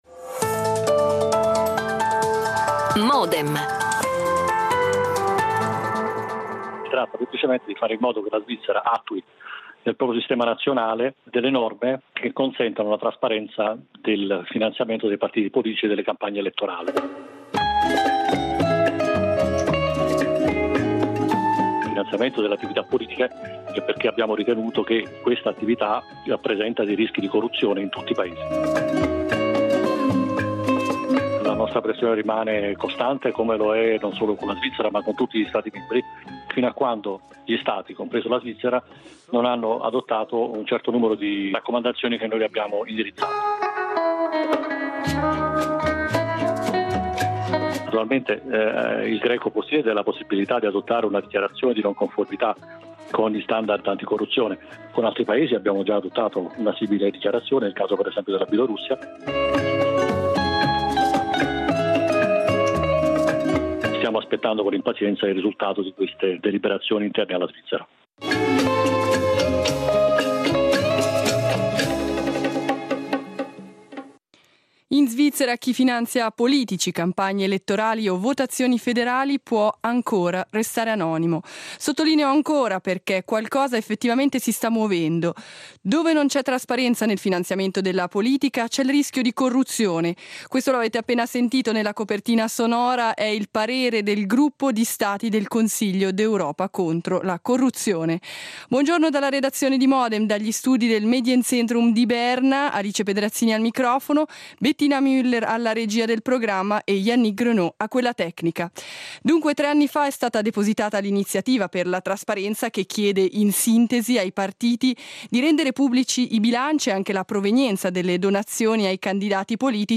Ne discutiamo da Berna con: Piero Marchesi, consigliere nazionale UDC Greta Gysin, consigliera nazionale Verdi Alex Farinelli, consigliere nazionale PLR Marco Romano, consigliere nazionale PPD
L'attualità approfondita, in diretta, tutte le mattine, da lunedì a venerdì